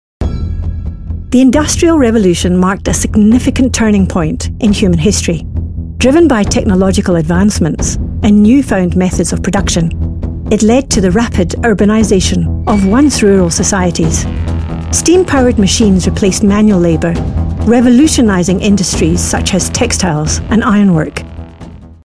authoritative, character, mature, nurturing, seasoned, wise
45 - Above
My demo reels